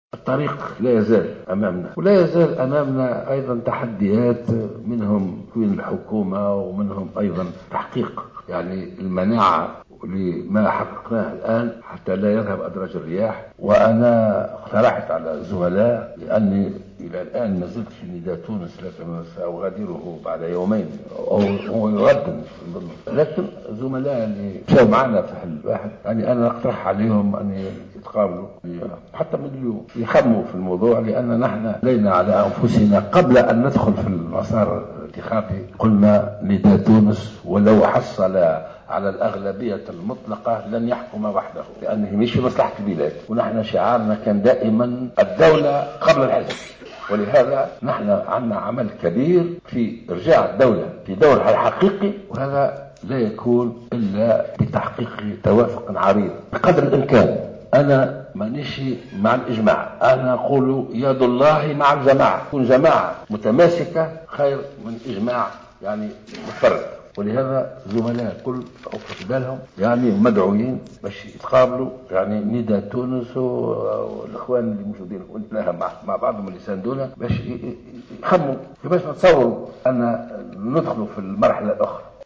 Dans une conférence de presse tenue aujourd’hui matin, Béji Caïed Essebsi a affirmé qu’il quittera Nidaa Tounes dans 2 jours.